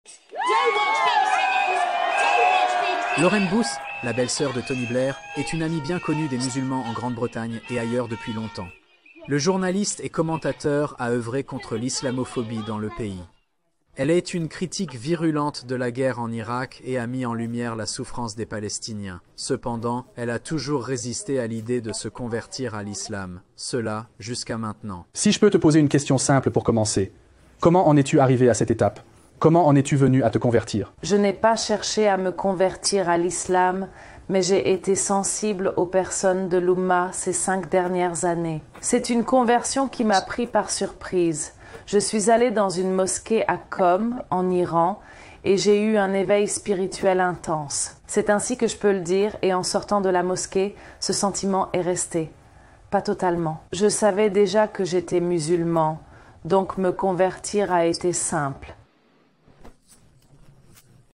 Description: Dans cette vidéo, Lauren Booth, la belle-sœur de Tony Blair, raconte sa conversion à l'Islam.